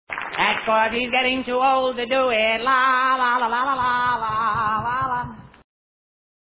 Bobby also demonstrated his versatility in this hour with his singing, impressions, acting and playing the guitar and piano.